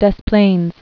(dĕs plānz)